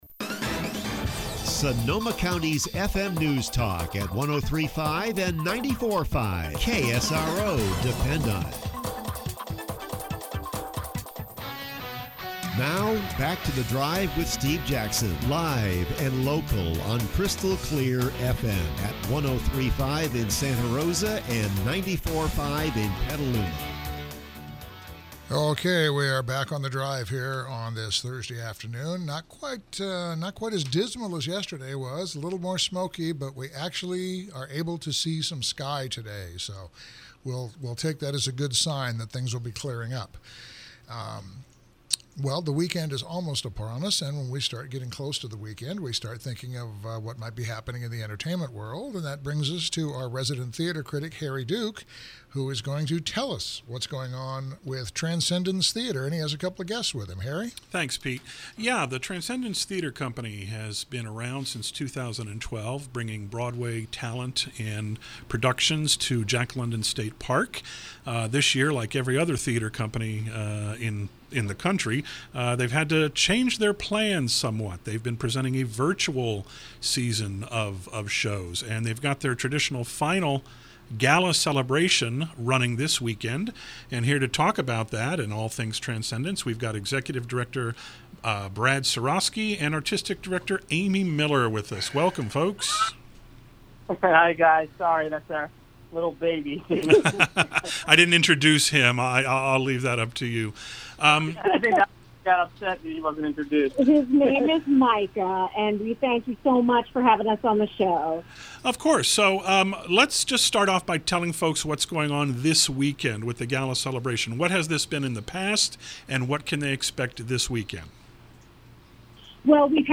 KSRO Interview – Transcendence Gala Celebration